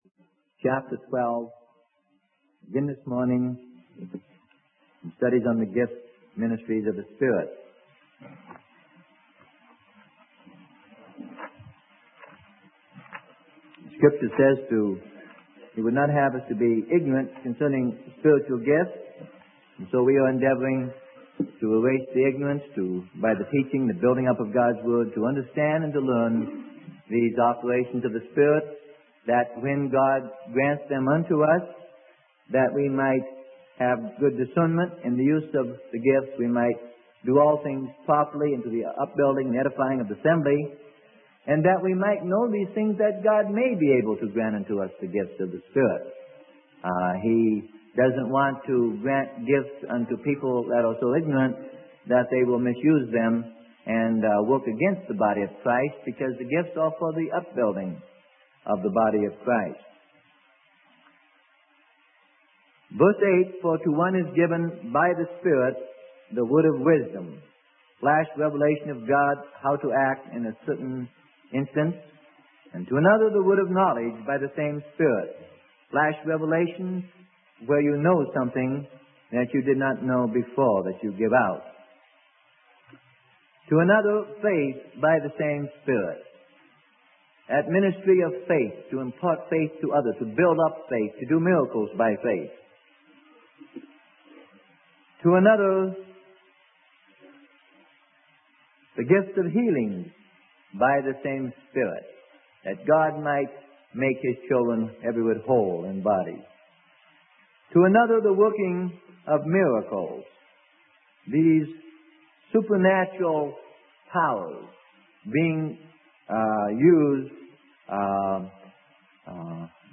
Sermon: Gifts of the Spirit - Part 9: Prophecy and Prophets - Freely Given Online Library